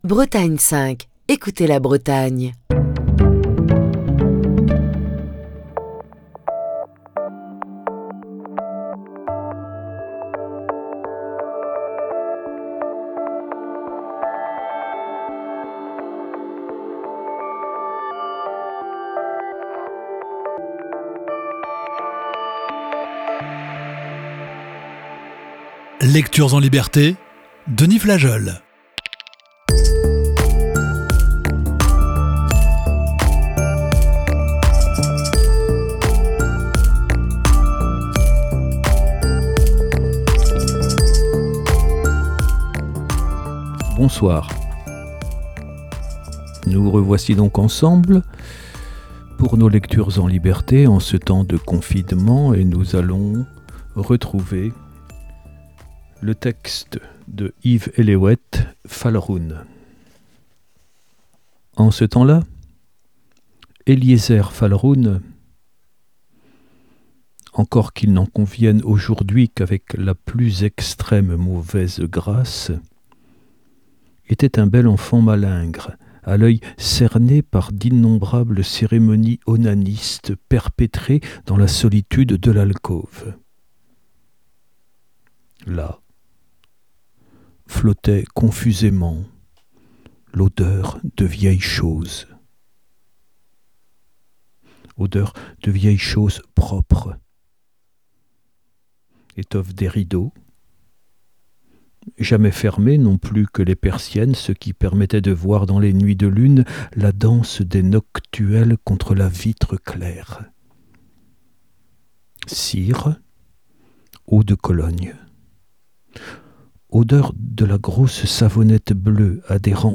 la lecture